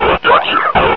/hl2/sound/npc/combine_soldier/test/near/